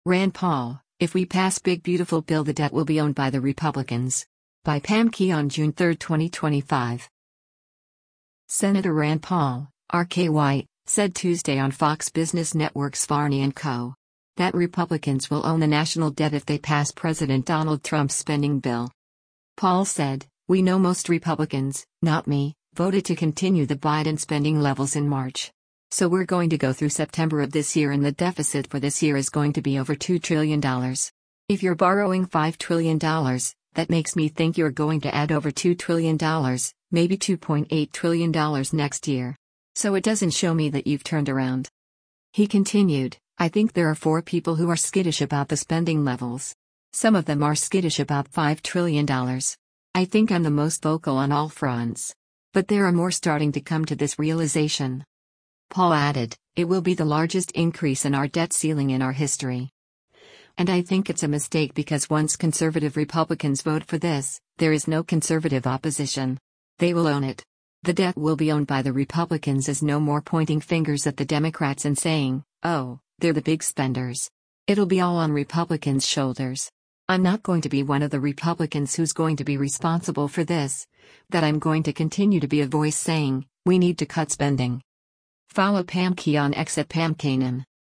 Senator Rand Paul (R-KY) said Tuesday on Fox Business Network’s “Varney & Co.” that Republicans will own the national debt if they pass President Donald Trump’s spending bill.